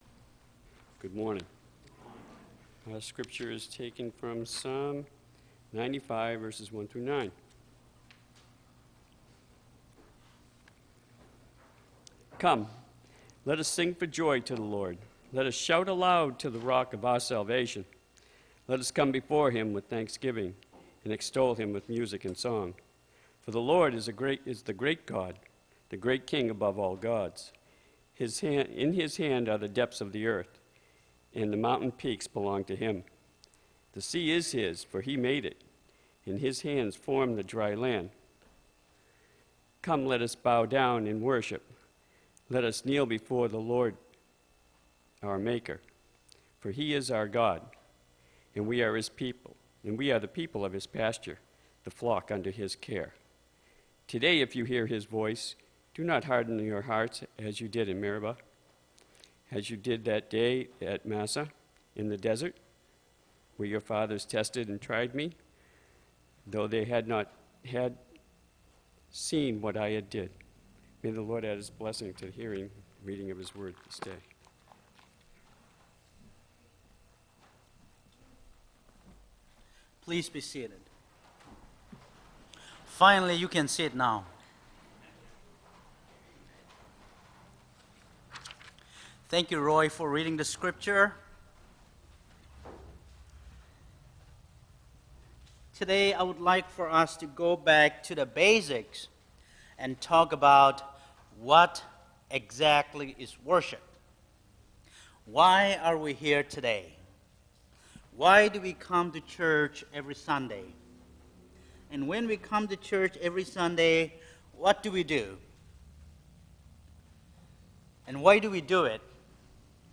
Audio Recording of August 30th Worship Service – Now Available
The audio recording of our latest Worship Service is now available.